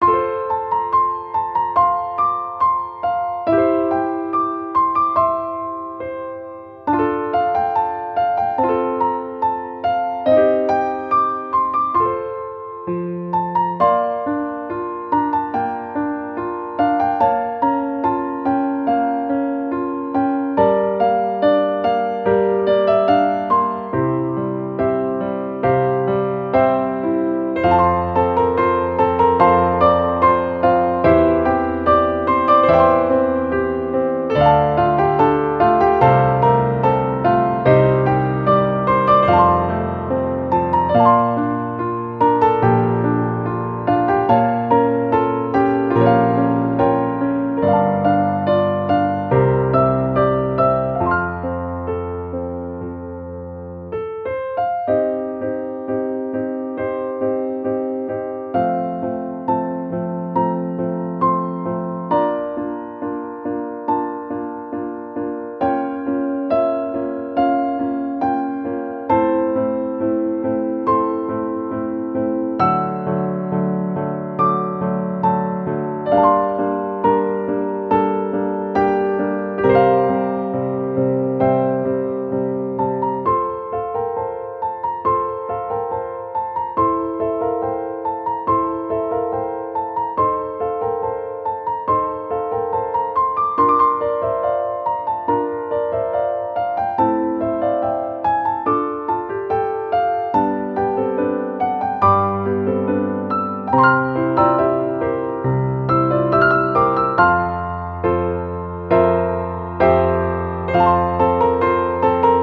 • 明るくほがらかなピアノ曲のフリー音源を公開しています。
ogg(L) - ゆったり 瑞々しい バラード
歌うようにピアニスティックに。